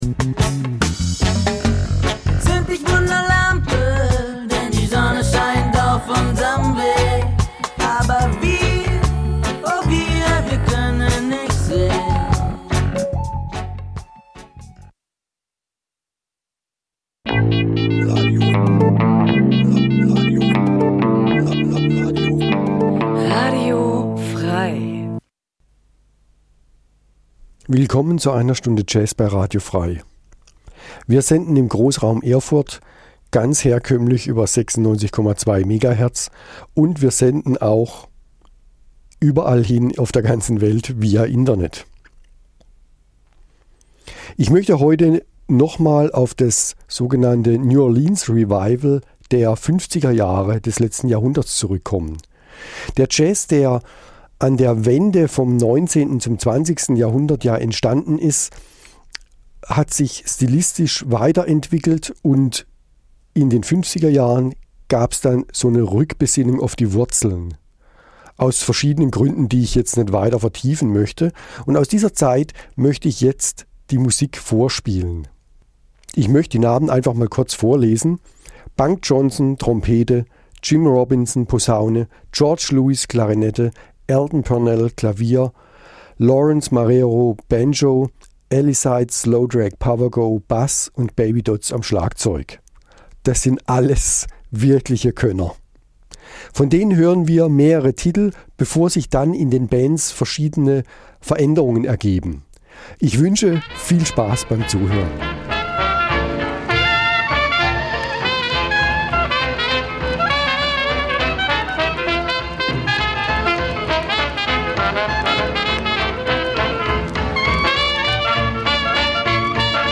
Eine Stunde Jazz